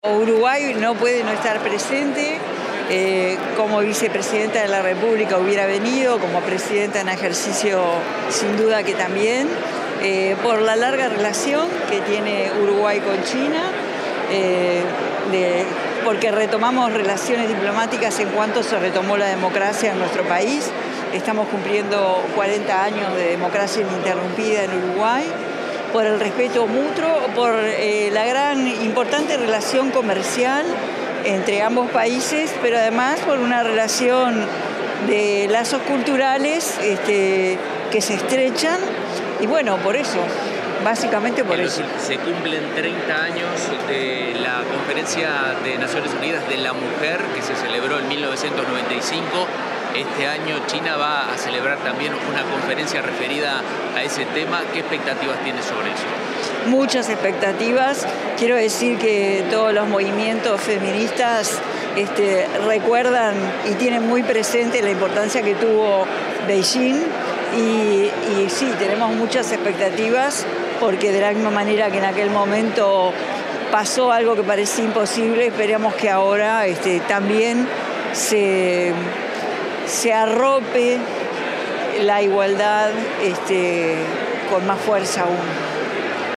Declaraciones de la presidenta en ejercicio, Carolina Cosse
La presidenta de la República en ejercicio, Carolina Cosse, diálogo con la prensa sobre las relaciones bilaterales con la República Popular China,